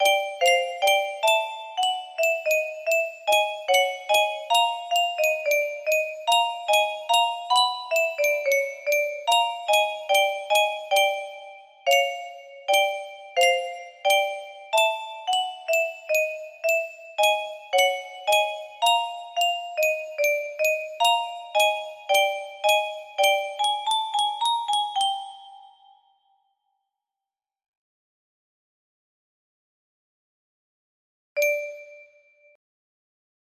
Music box music for my roleplays